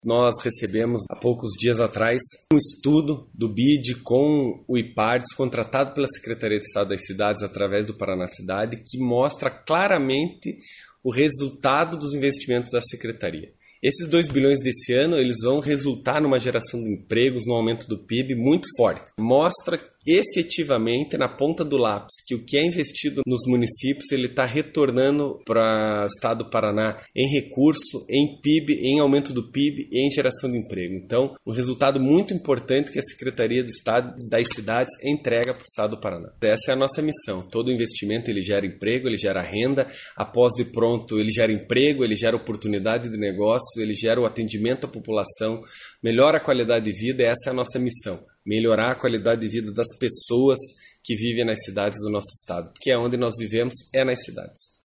Sonora do secretário das Cidades, Eduardo Pimentel, sobre estudo da Fipe a respeito dos valores de repassados aos municípios a título de financiamento